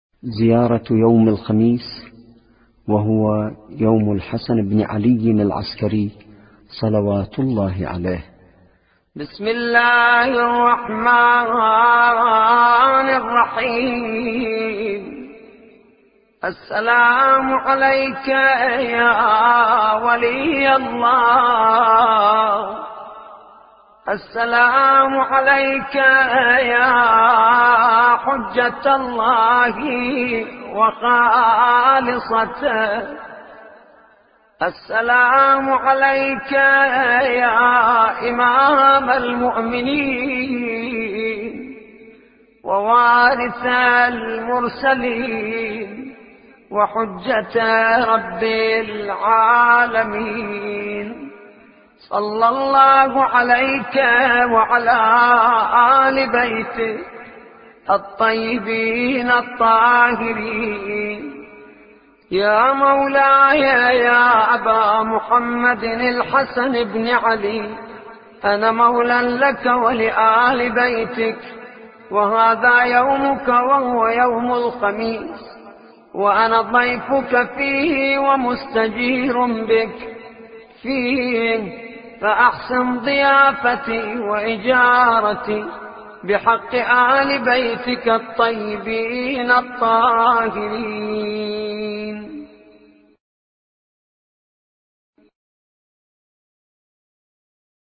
زيارة الإمام العسكري(ع)